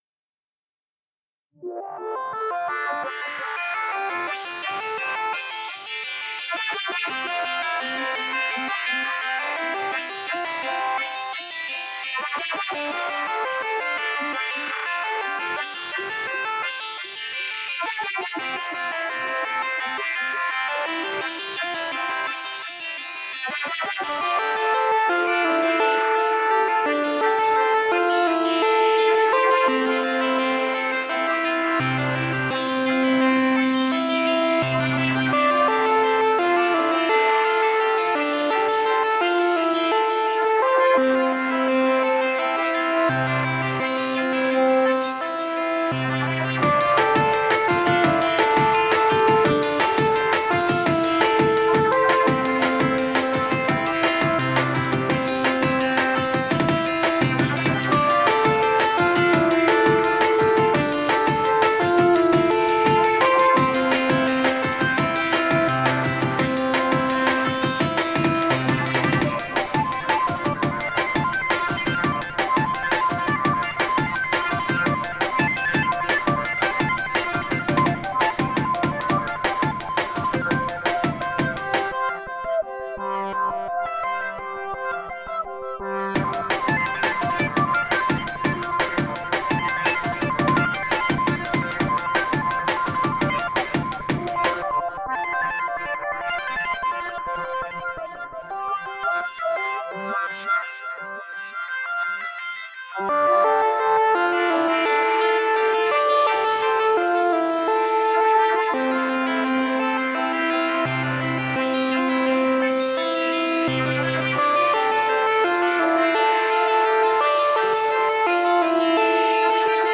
Idm and minimalist electronica..
Tagged as: Electronica, Ambient, IDM